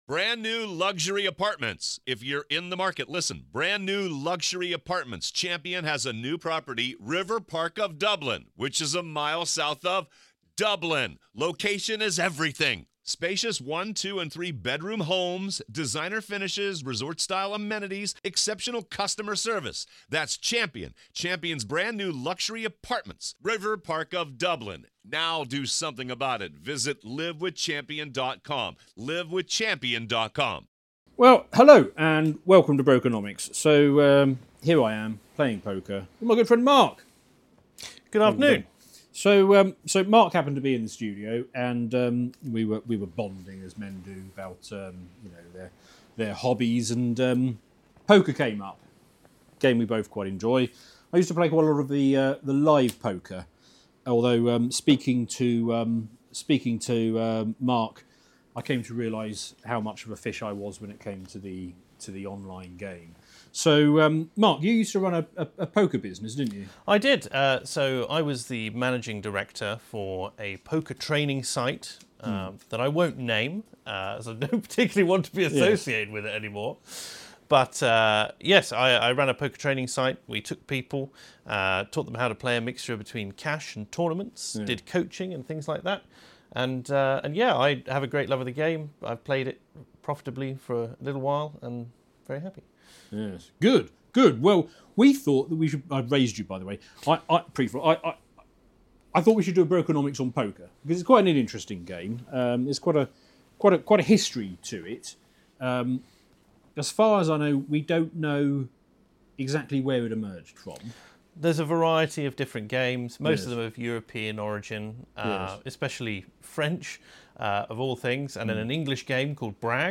Whilst doing so they chat through the history of the game, the maths and practice of winning in poker.